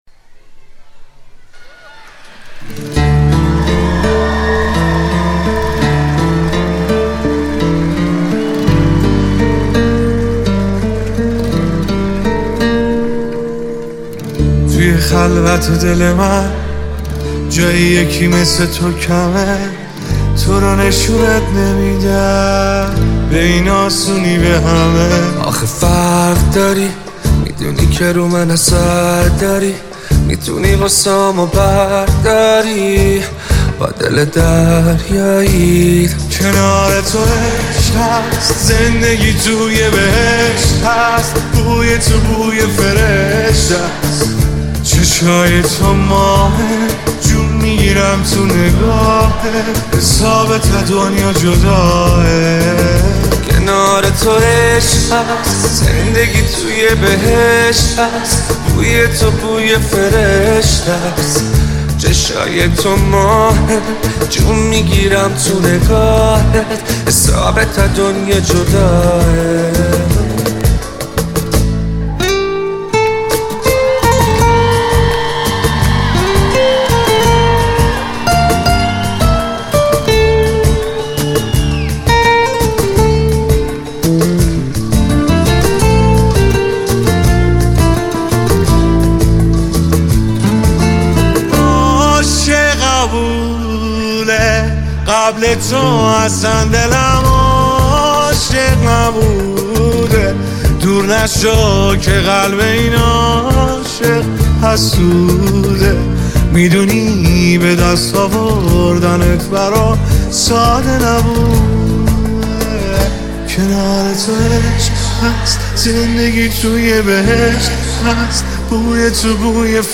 دو خواننده پاپ